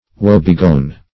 Woe-begone \Woe"-be*gone`\, a. [OE. wo begon.